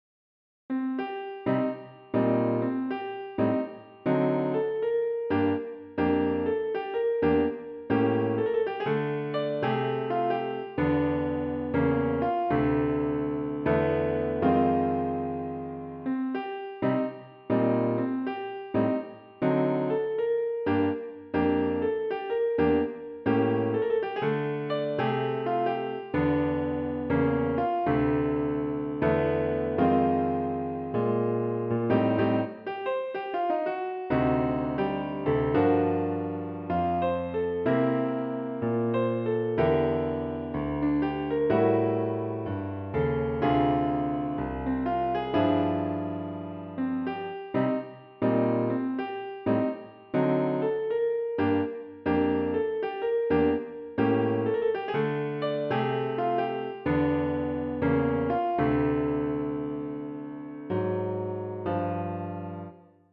In this case the 7th degree of the scale is not raised so the piece takes on a smoother texture (the sharp 7 drives harder to the tonic).
The ‘B’ section reflects the same chord changes as the ‘A’ section but adds some rhythmic contrast with nice little punch chords.